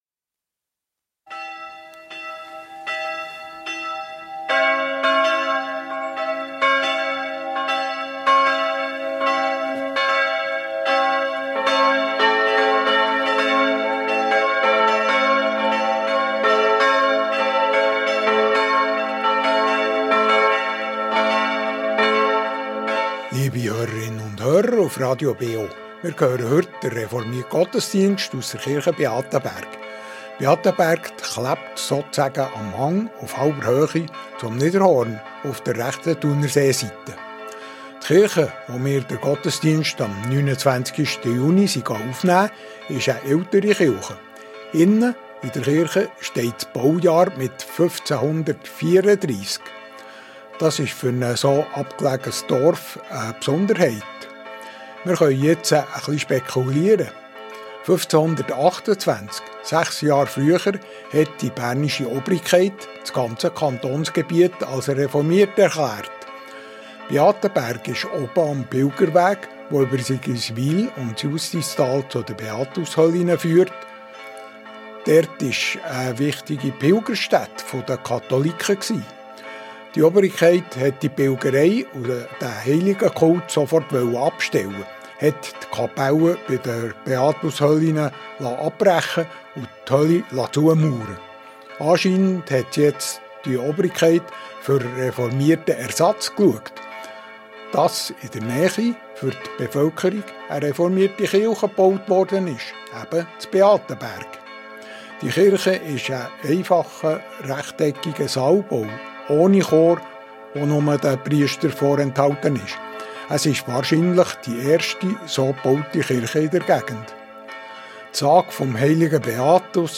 Reformierte Kirche Beatenberg ~ Gottesdienst auf Radio BeO Podcast